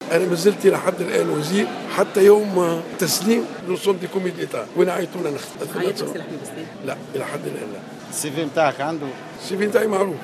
أكد وزير الشؤون الاجتماعية أحمد عمار الينباعي في تصريح اعلامي اليوم الاثنين أنه لم يتلقى اي اتصال من رئيس الحكومة المكلف للمشاركة في الحكومة القادمة مؤكدا استعداده للعمل في اي موقع .